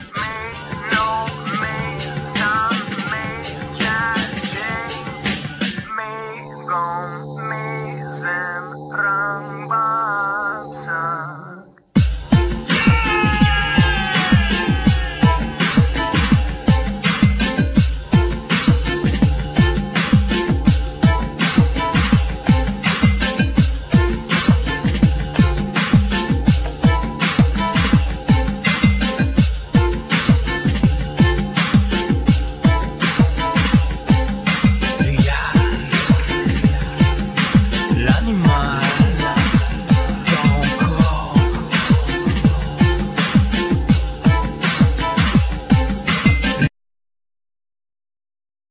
Vocal,Programming
Cello
Congas,Doundoun,Ti-bois
Kit percussions,Doudoumba
Gaida
Tin whistle
Dof